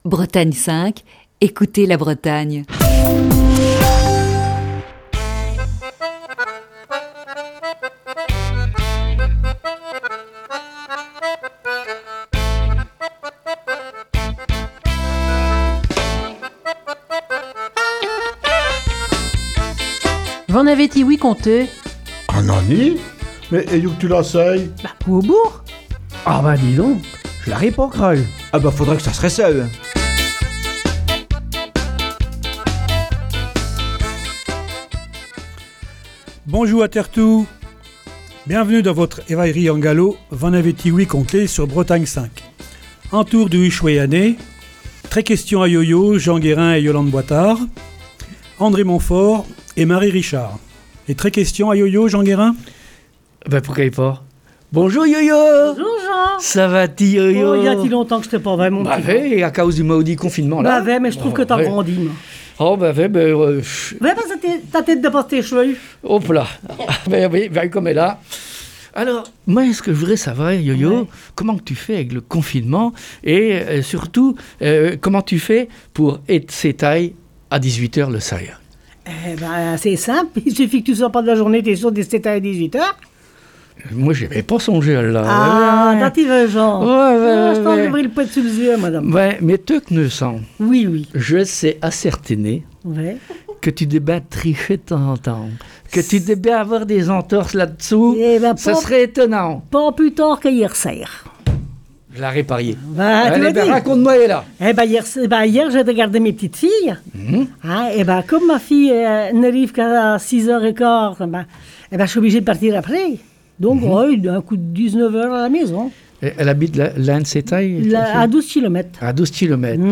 Ce matin, la troupe de V'en avez-ti ouï conté ? est au grand complet autour des micros de Bretagne 5 pour aborder un thème d'actualité : Le couvre-feu.